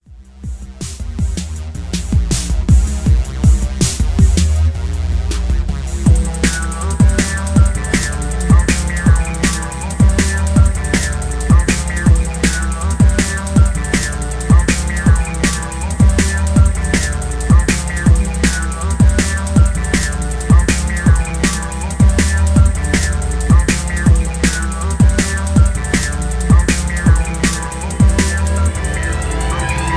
Tags: dnb